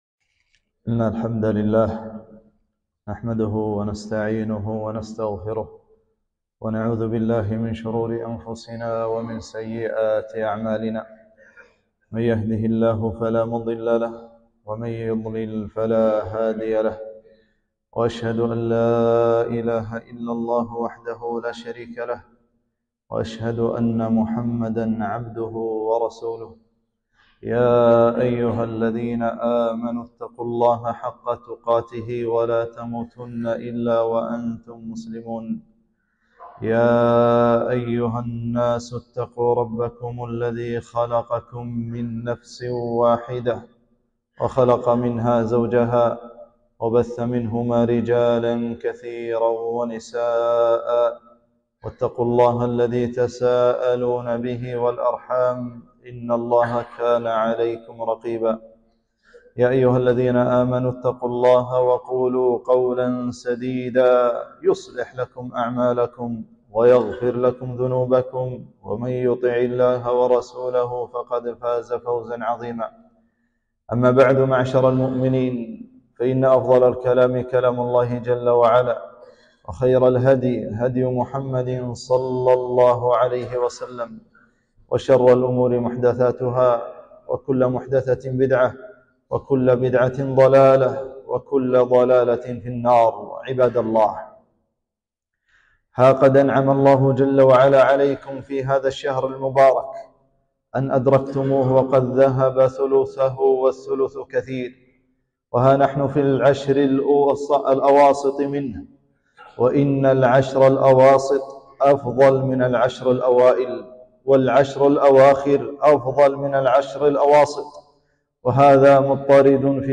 خطبة - انتصف شهر رمضان